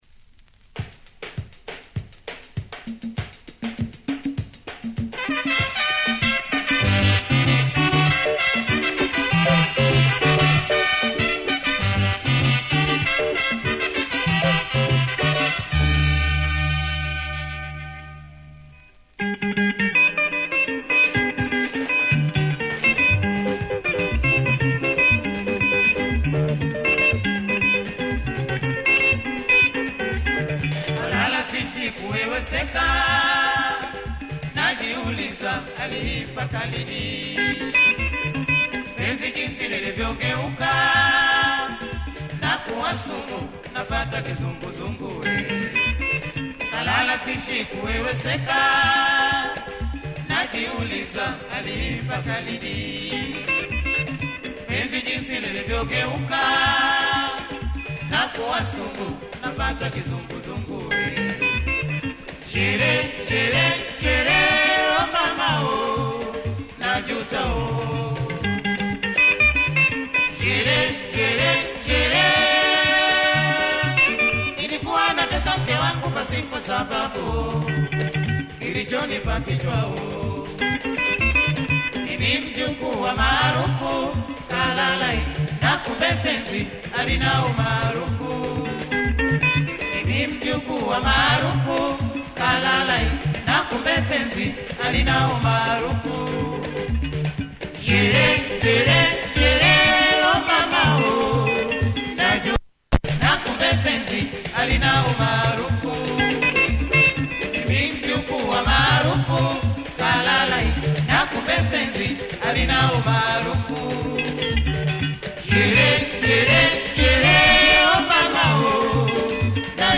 proper dancer!